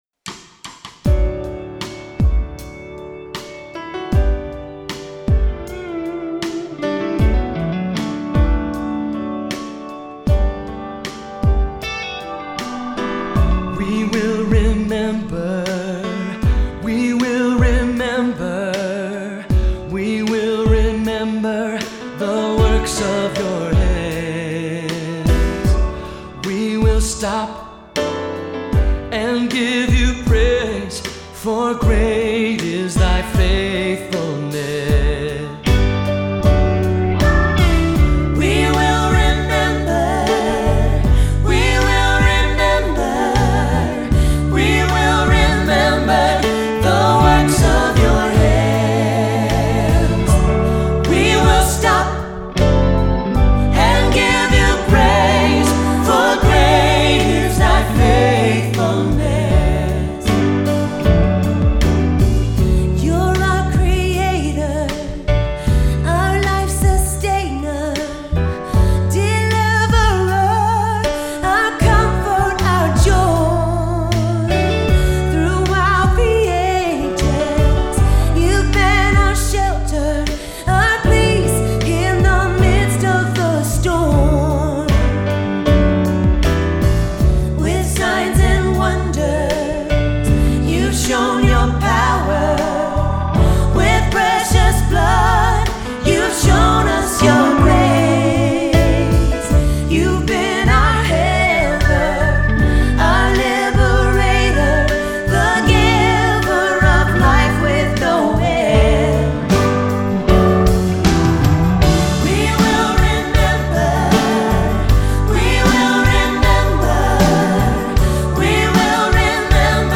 Choir Music